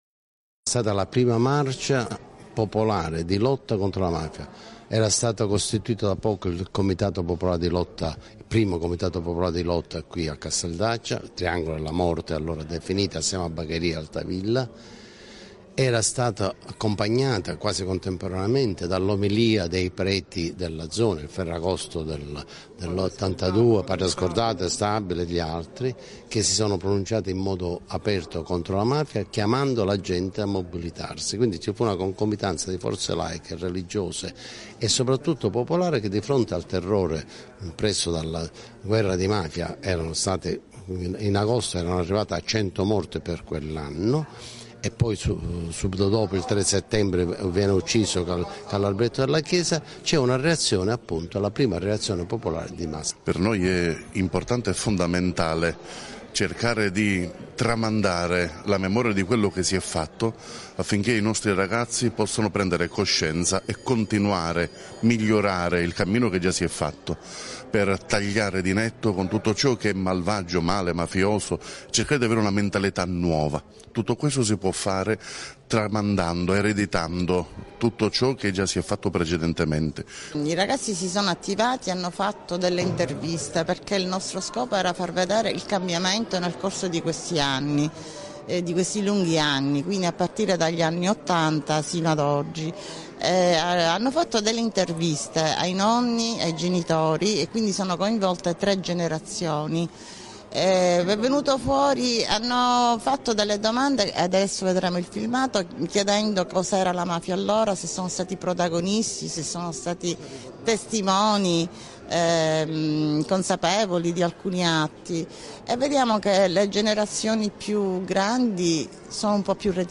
TELE ONE - Intervista